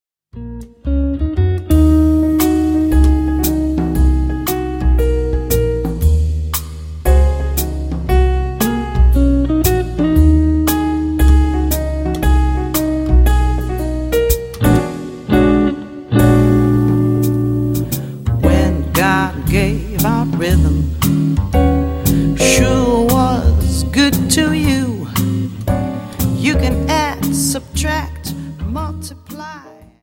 Dance: Slowfox 28s